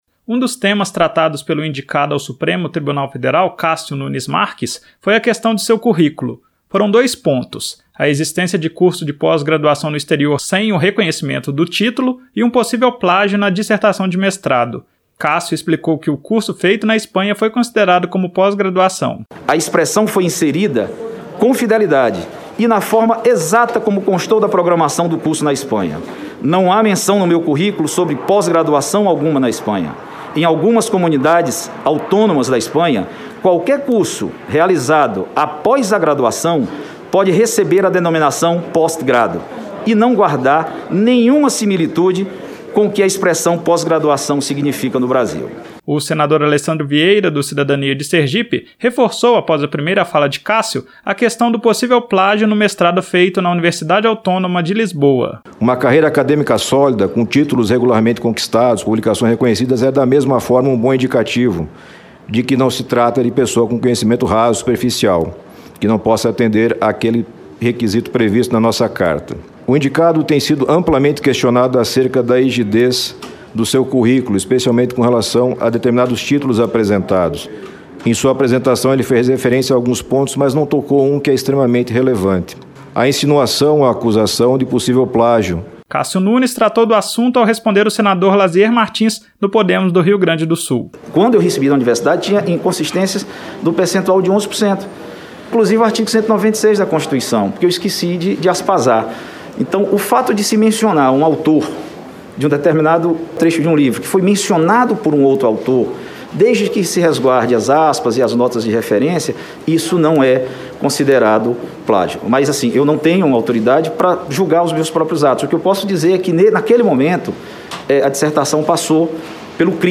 Os senadores Alessandro Vieira (Cidadania-SE) e Lasier Martins (Podemos-RS) questionaram o indicado sobre as possíveis inconsistências em seu currículo acadêmico. Mais informações na reportagem